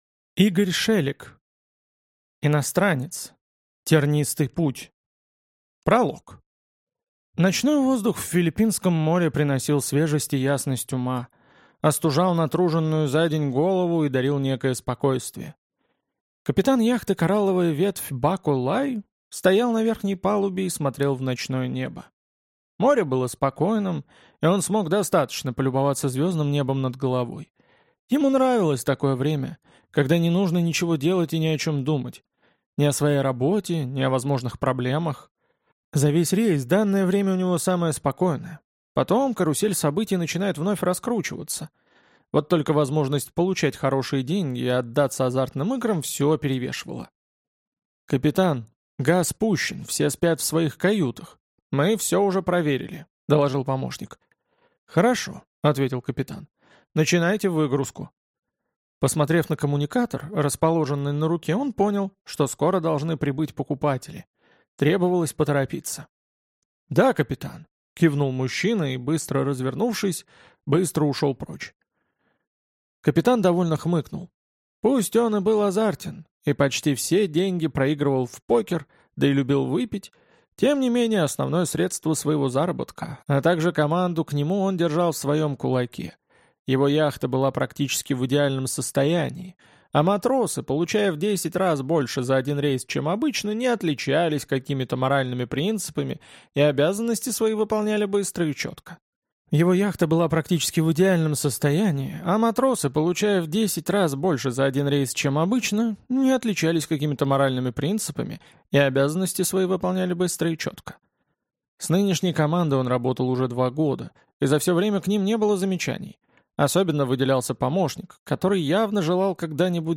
Аудиокнига Иностранец. Тернистый путь | Библиотека аудиокниг